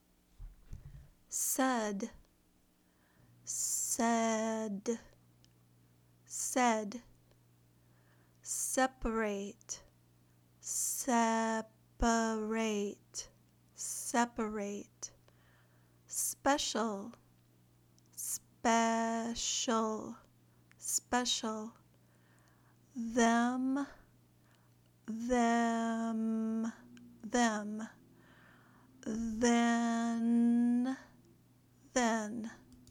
Review the Short “E” Sound
Practice Short “E”